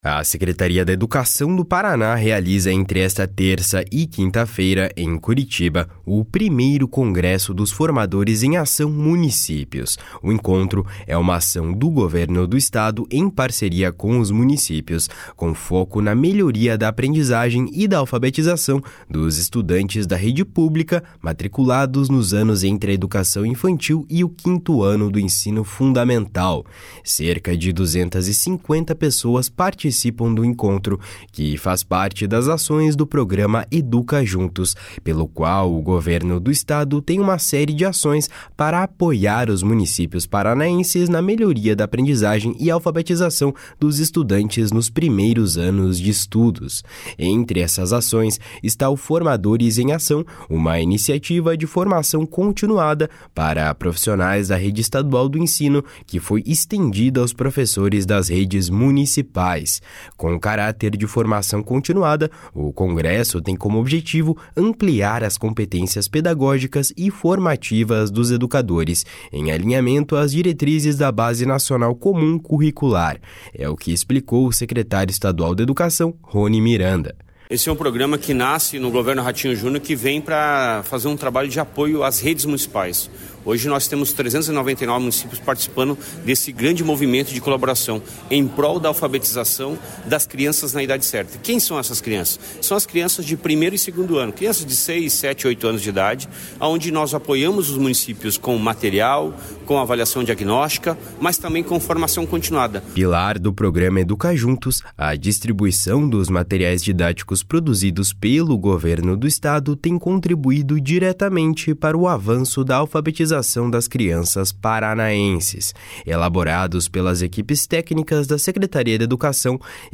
Com caráter de formação continuada, o congresso tem como objetivo ampliar as competências pedagógicas e formativas dos educadores, em alinhamento às diretrizes da Base Nacional Comum Curricular. É o que explicou o secretário estadual da Educação, Roni Miranda. // SONORA RONI MIRANDA //